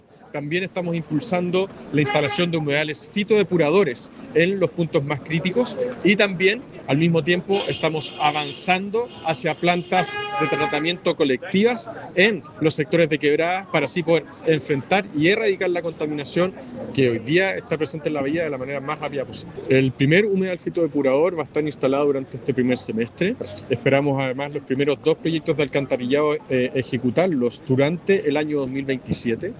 En tal línea, el alcalde Tomás Gárate abordó los plazos para la instalación de humedales fitodepuradores y las conexiones al alcantarillado de viviendas que botan aguas servidas indirectamente al Lago.